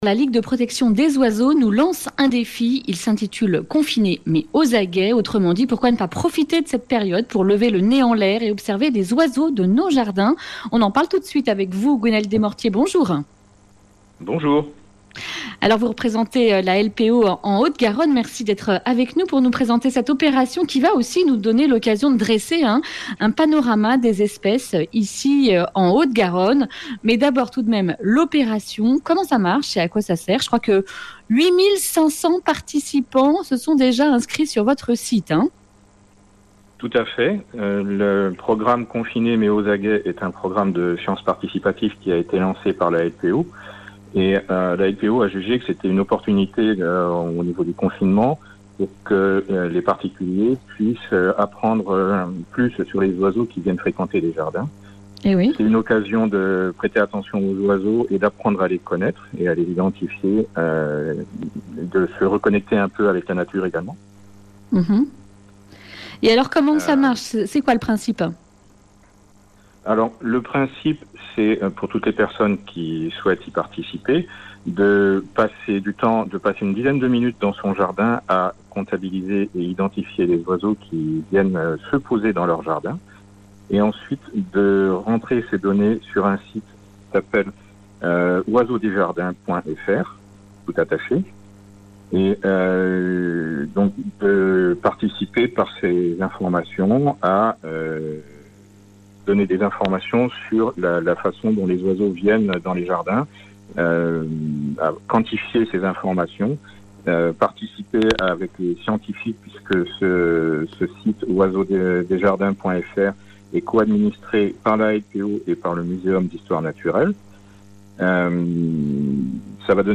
Accueil \ Emissions \ Information \ Régionale \ Le grand entretien \ Et si vous observiez les oiseaux pendant le confinement ?